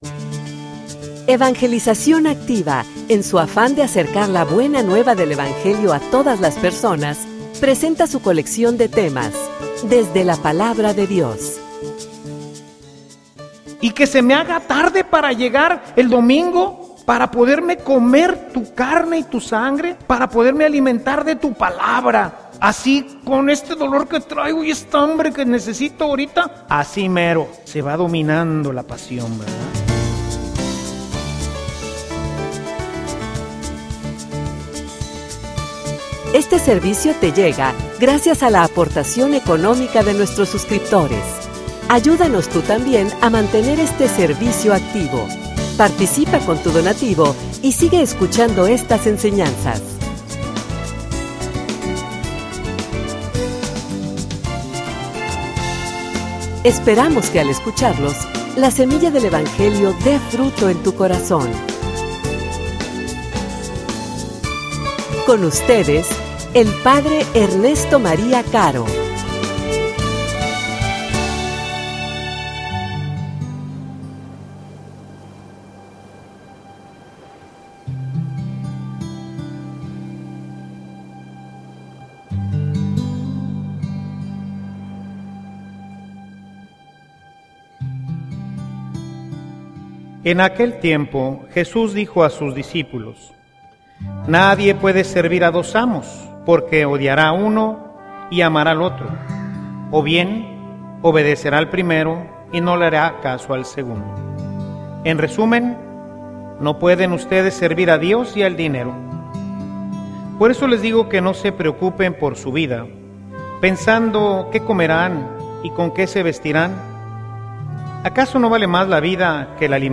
homilia_Jerarquiza.mp3